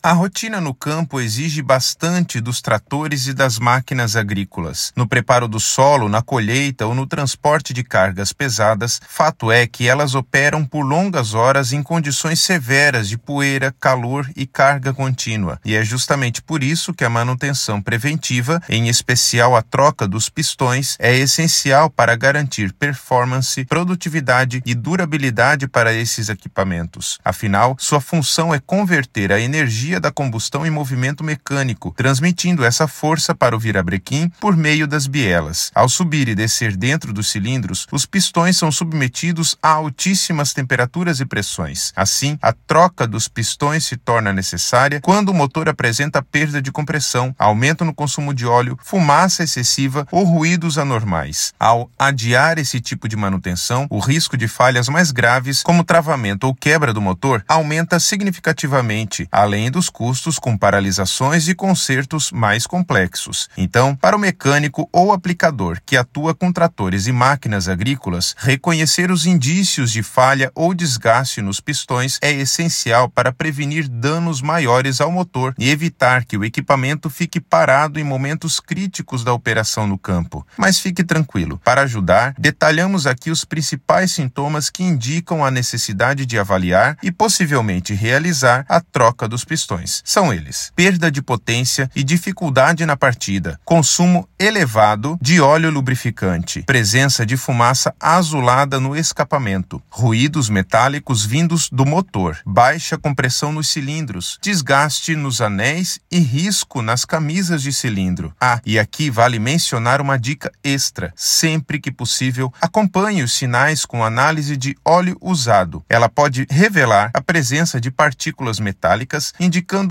Narracao-03-troca-dos-pistoes.mp3